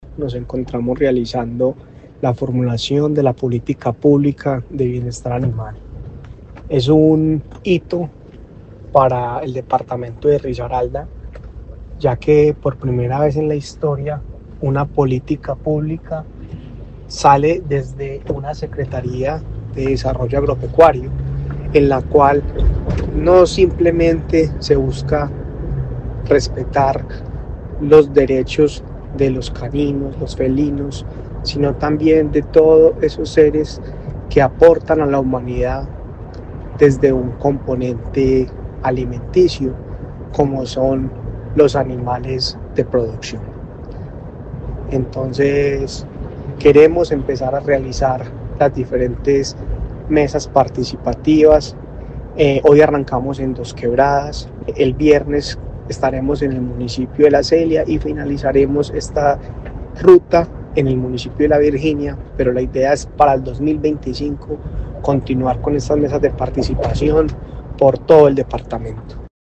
El evento inaugural, realizado en el Concejo Municipal de Dosquebradas, reunió a representantes de la comunidad, expertos en bienestar animal, gremios productivos y entidades del Estado, marcando el inicio de una serie de mesas participativas que se llevarán a cabo en todos los municipios del departamento.